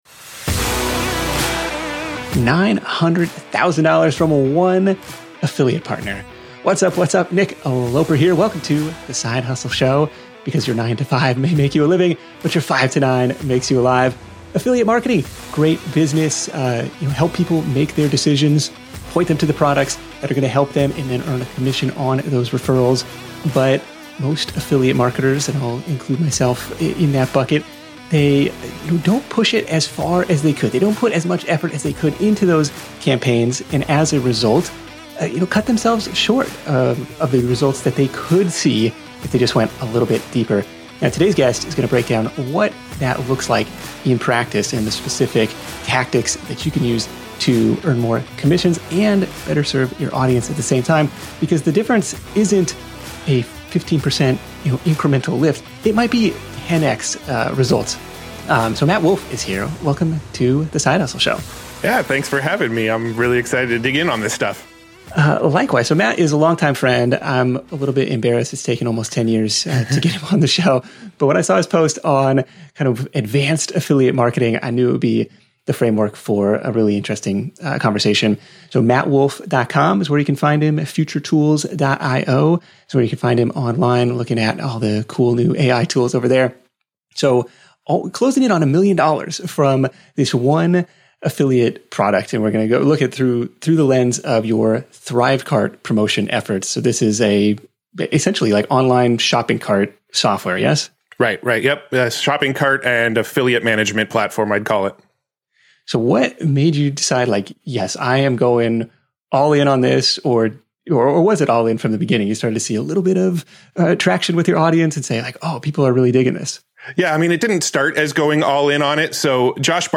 Tune in to the Side Hustle Show interview